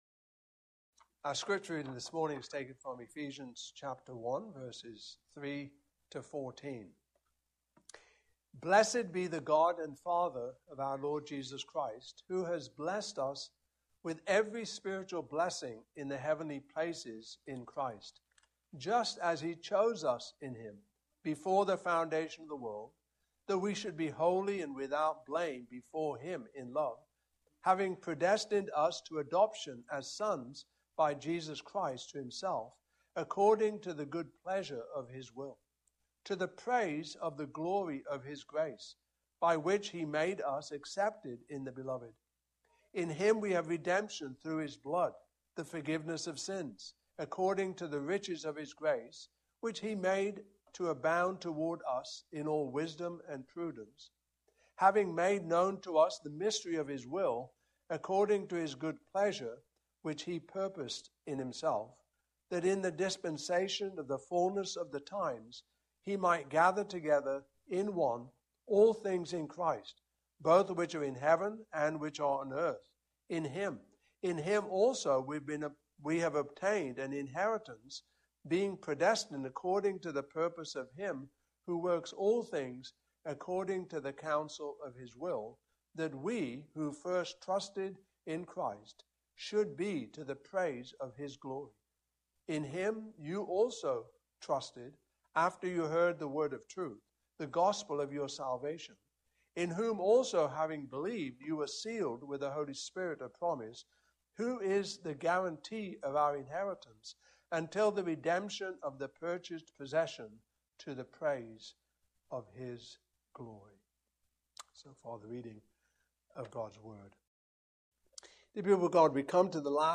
Ephesians 1:3-14 Service Type: Morning Service Topics: Solo Deo Gloria